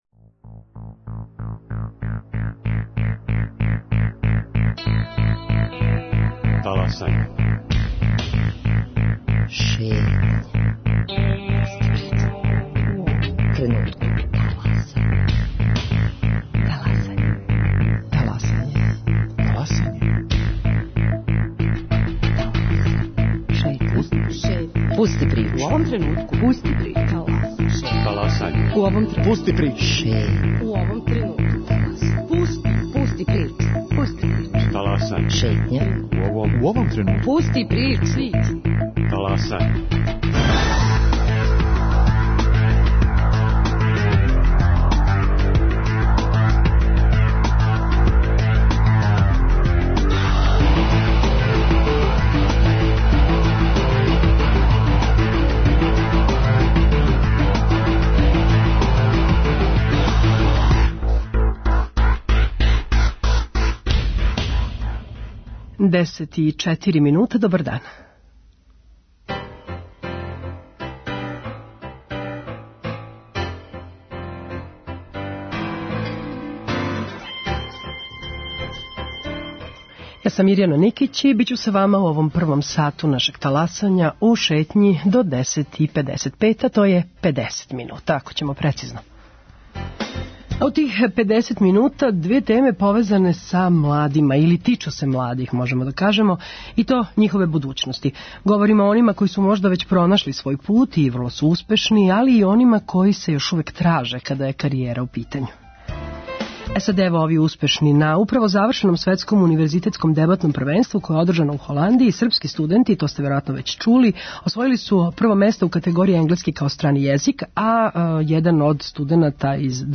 Они су данас гости Шетње.